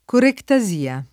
[ korekta @& a ]